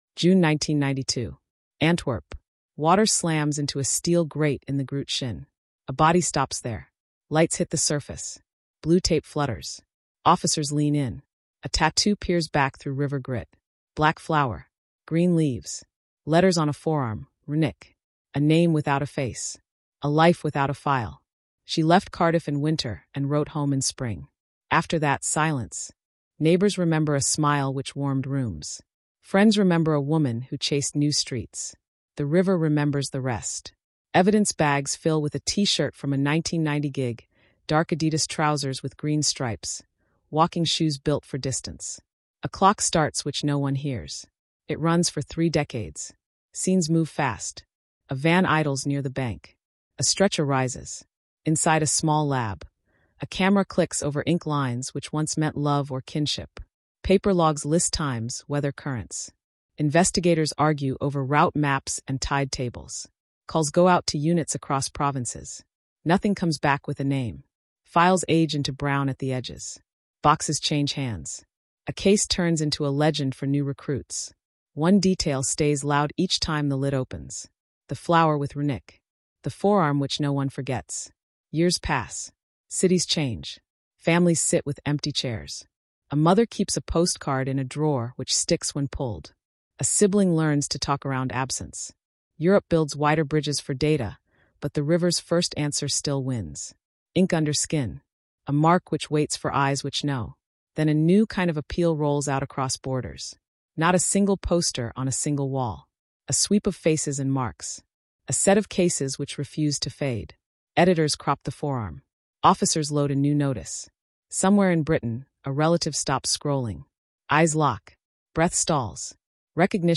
Our neutral narrator leads listeners through this true crime cold case with a captivating pace reminiscent of Netflix documentaries. Experience a unique crime investigation that reveals how memory, ink, and fingerprints converge on the pursuit of justice. Join us as we explore how cross-border methods and family connections play a pivotal role in this cold case.